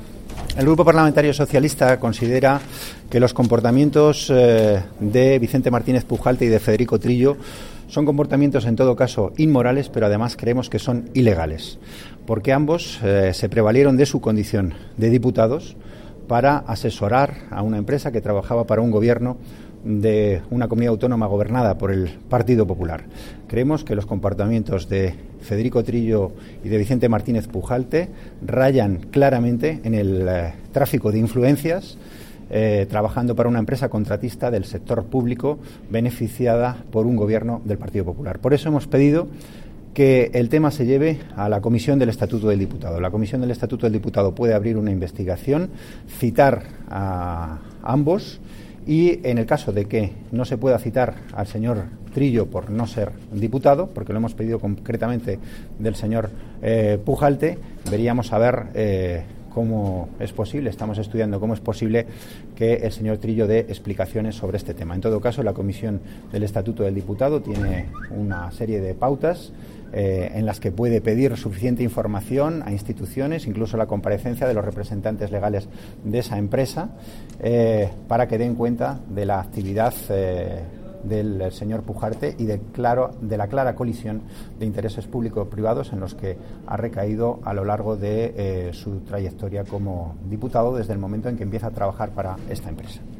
Declaraciones de Antonio Hernando 28/04/2015